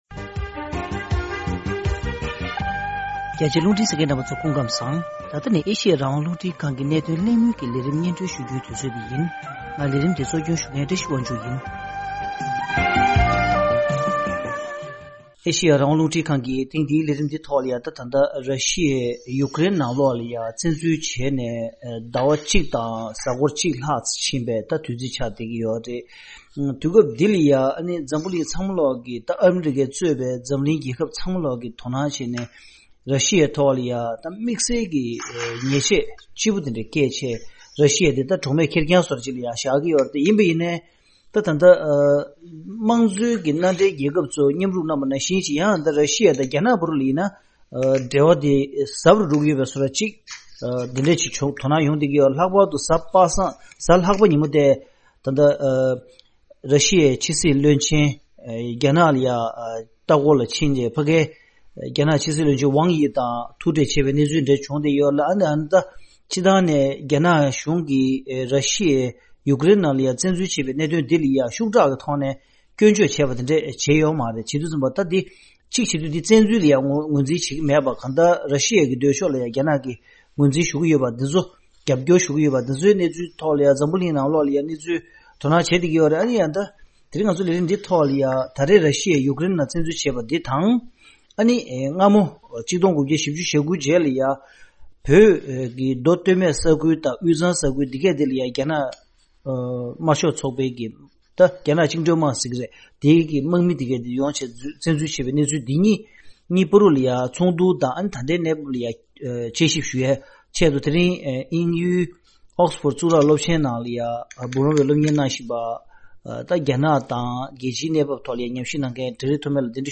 ར་ཤི་ཡས་ཡུ་ཀརེན་ནང་བཙན་འཛུལ་དང་རྒྱ་ནག་གིས་བོད་ནང་བཙན་འཛུལ་ལ་མཚུངས་བསྡུར་བྱས་པའི་དཔྱད་གླེང་།